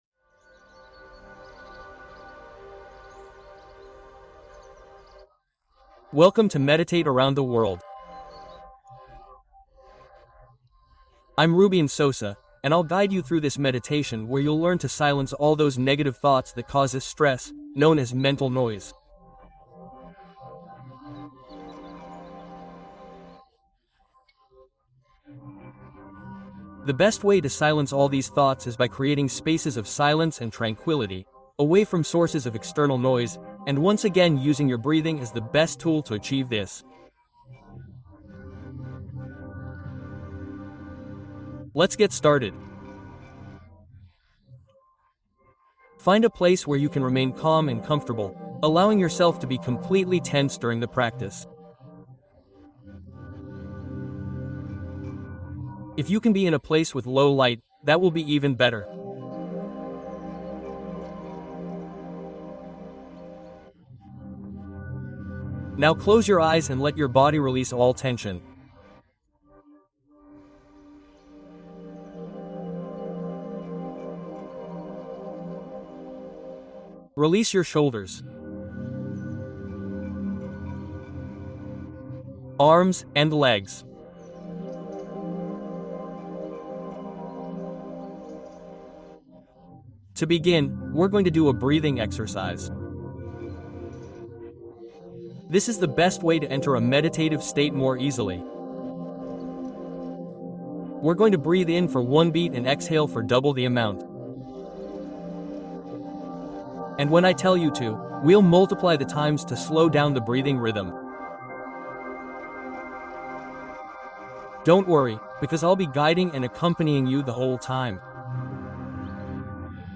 Sanación Profunda: Meditación de 10 Minutos para Regenerar desde la Mente